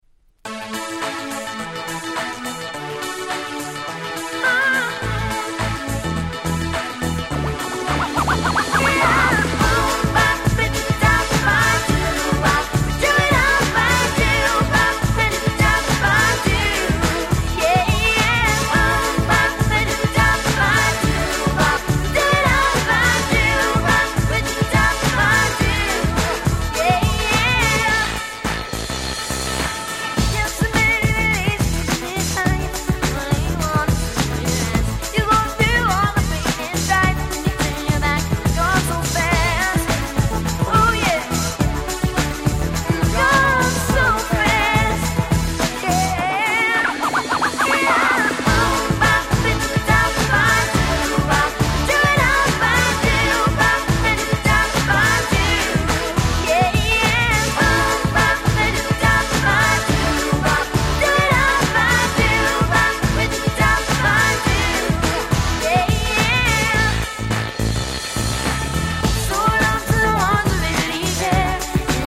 ※試聴ファイルは別の盤から録音してあります。
アメリカ、オクラホマ生まれの兄弟ユニット。